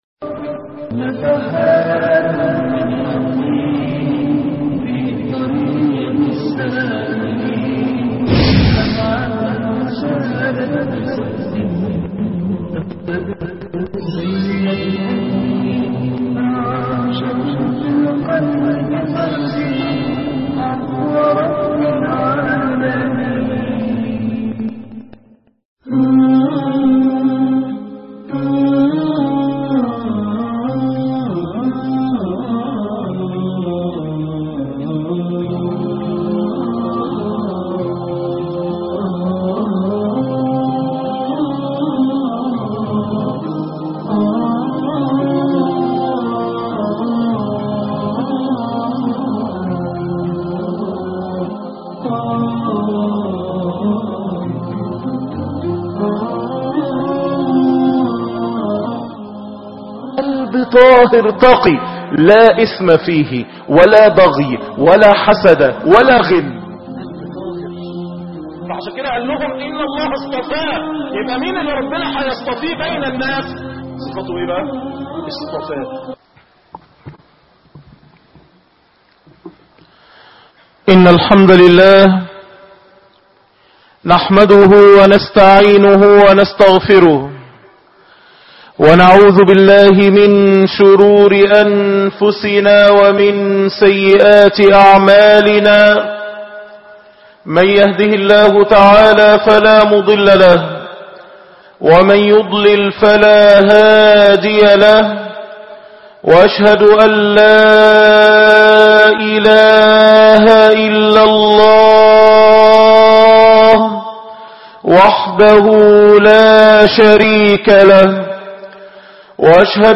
الجيل الجديد( 6/12/2013)خطب الجمعة